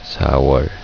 Sa-wol